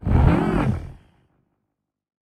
Minecraft Version Minecraft Version 1.21.4 Latest Release | Latest Snapshot 1.21.4 / assets / minecraft / sounds / mob / warden / listening_5.ogg Compare With Compare With Latest Release | Latest Snapshot
listening_5.ogg